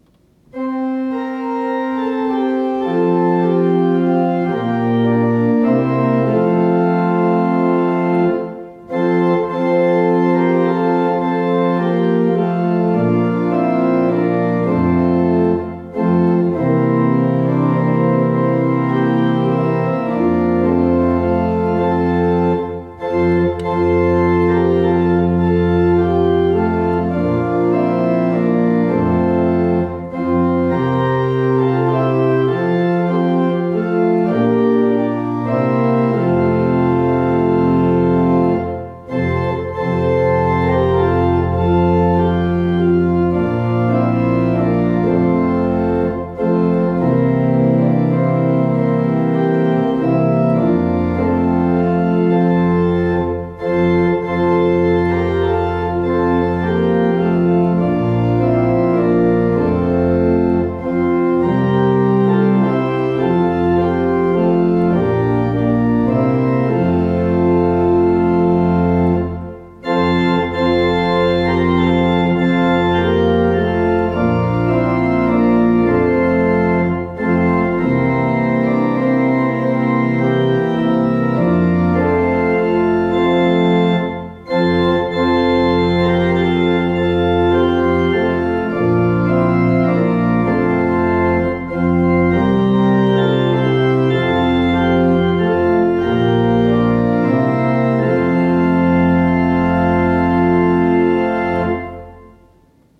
Organ Accompaniment